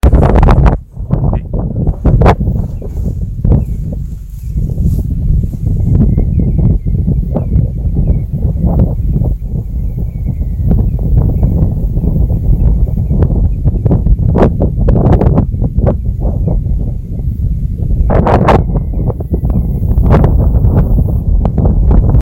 Chorlo Cabezón (Oreopholus ruficollis)
Nombre en inglés: Tawny-throated Dotterel
Provincia / Departamento: Entre Ríos
Condición: Silvestre
Certeza: Fotografiada, Vocalización Grabada